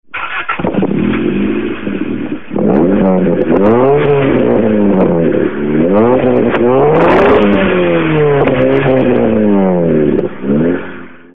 Hi leute.bin ein nicht gerade unerfahrener civic schrauber,habe dennoch ein problem!habe am wochen ende einem EG3 ne schönheits OP verpasst.bodykit,edelstahlsportauspuff in cup version :yes: (zwei hunderter rohre [mit schrägem einsatz] in der mitte,sonder anfertigung bei supersport) und nen sportluftfilter mit 113mm anschluß :D !
gehört zwar nicht zum thema aber der sound von deinem eg3 ist ja mal echt geil hast du bilder von deinem umbau gemacht ?